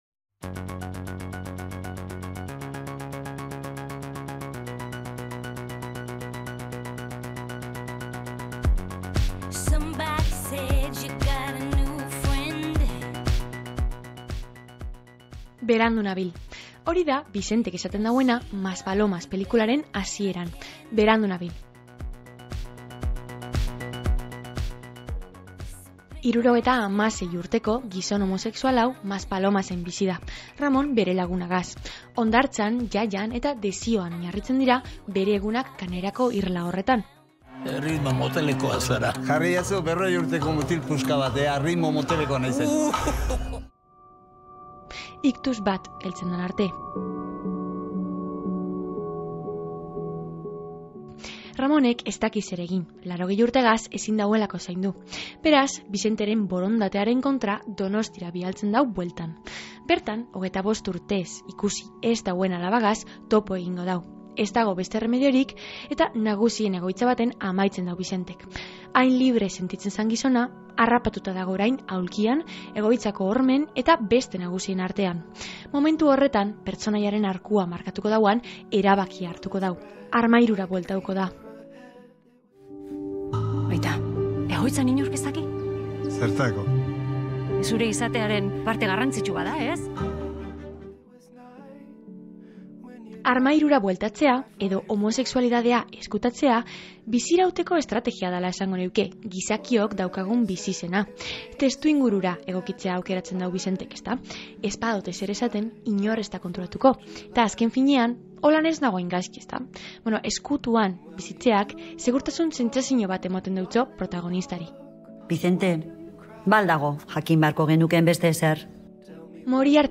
Aitor Arregi zuzendariaren berbak entzungo doguz, otsailean Goizeko Izarretan irratsaioan euki gendun-eta.